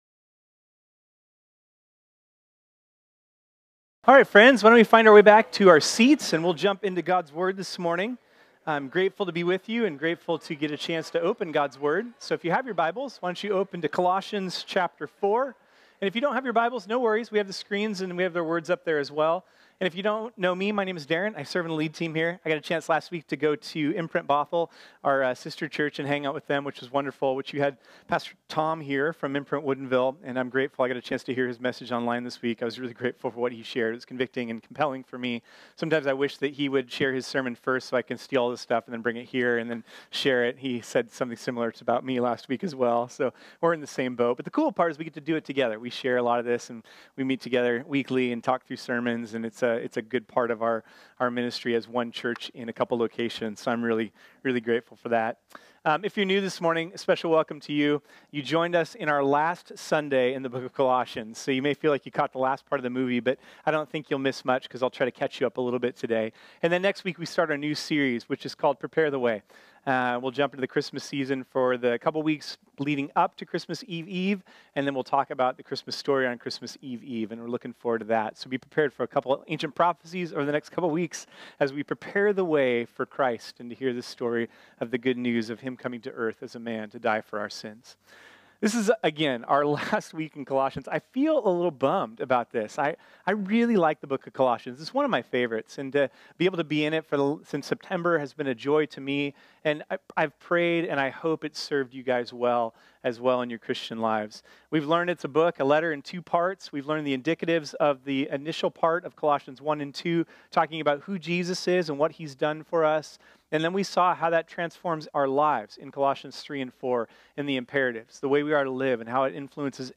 This sermon was originally preached on Sunday, December 2, 2018.